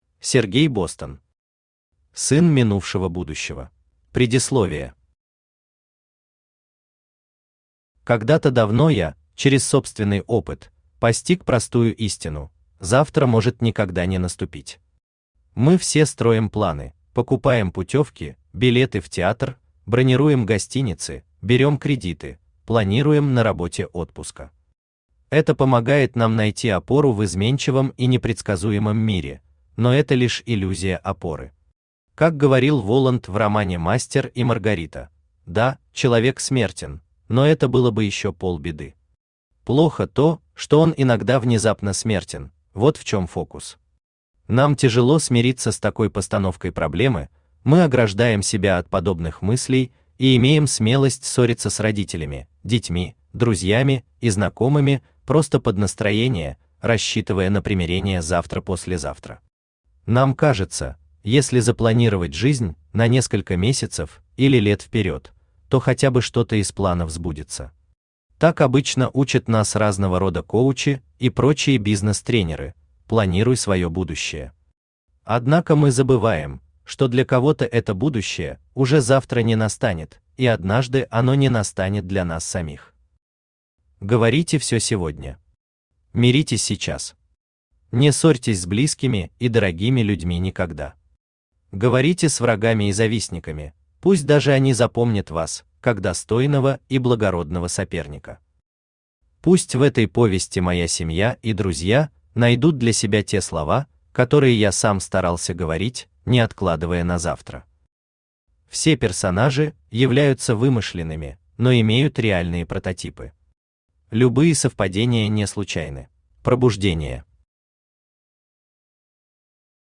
Аудиокнига Сын минувшего будущего | Библиотека аудиокниг
Aудиокнига Сын минувшего будущего Автор Сергей Бостон Читает аудиокнигу Авточтец ЛитРес.